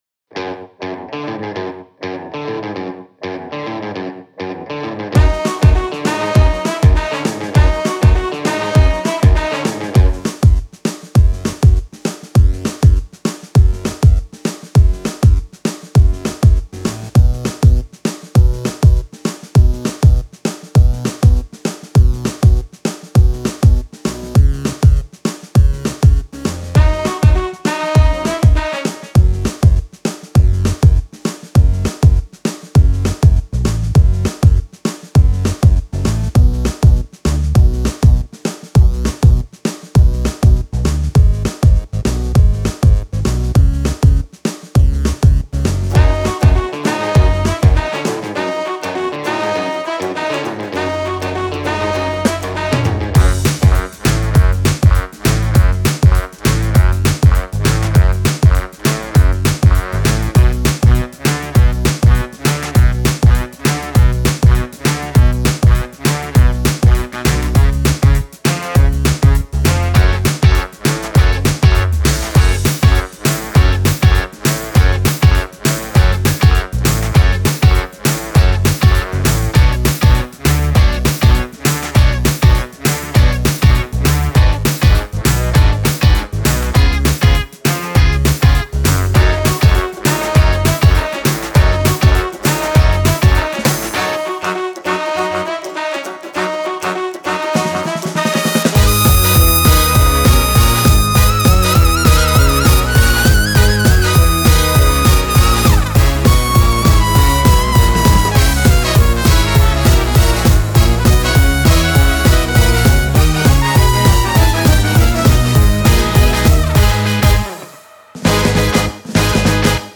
con-vain-cu-instrumental.mp3